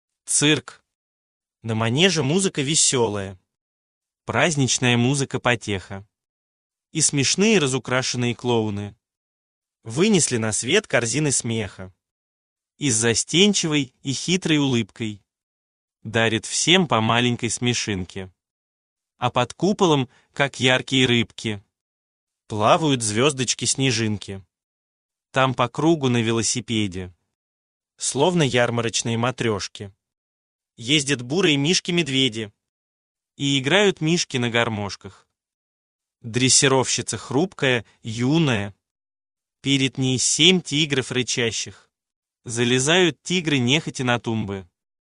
Аудиокнига Цирк. Книжка-раскраска | Библиотека аудиокниг